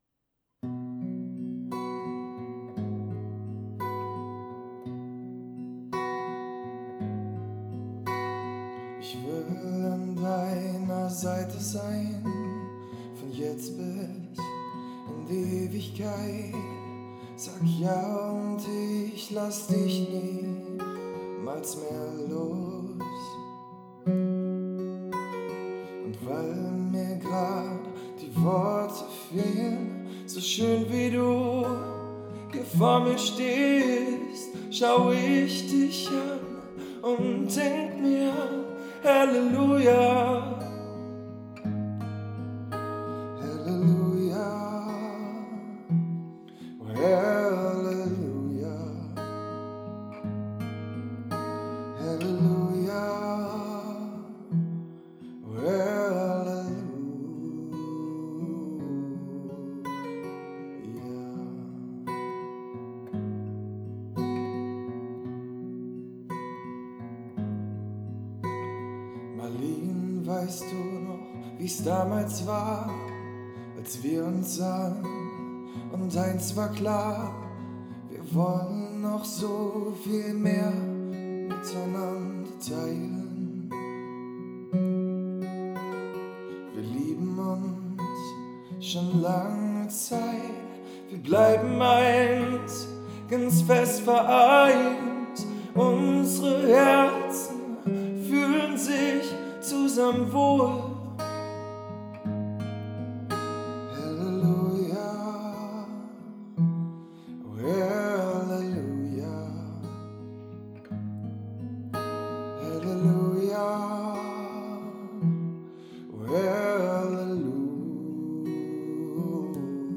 Hochzeit 2024